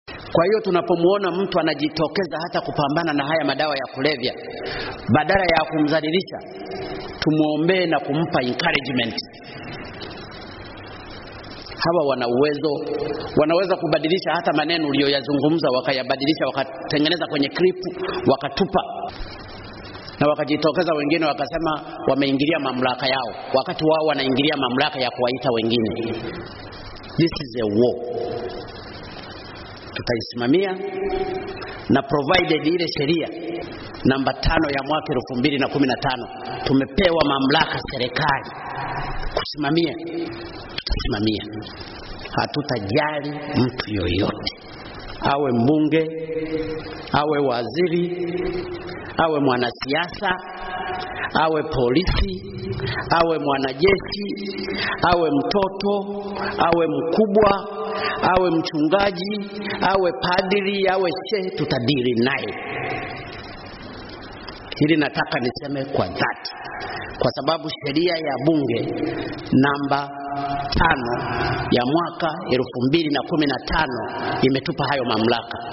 Rais John Magufuli anasema ni jukumu la serikali kupambana na dawa za kulevya